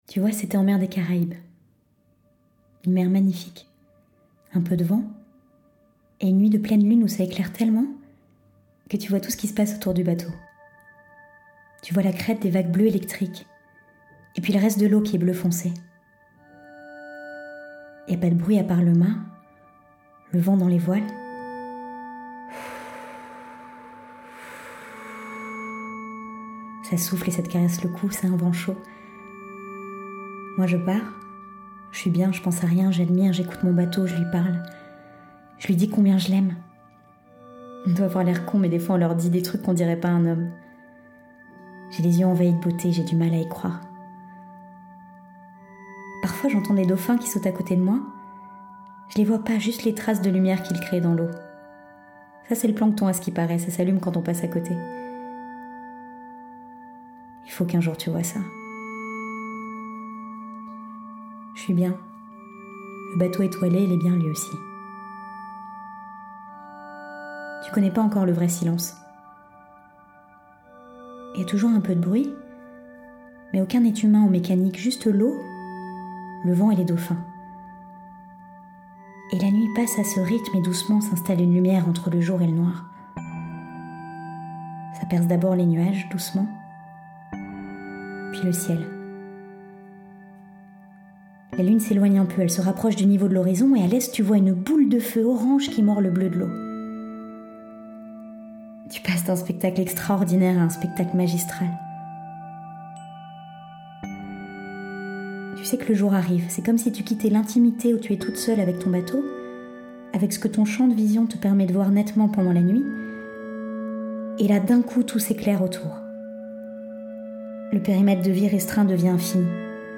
5-.Voix-off-pièce-de-théâtre-Lenfant-et-lhorizon.mp3